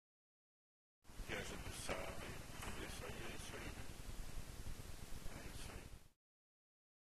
Instalação: fotografia, vidro e proposição sonora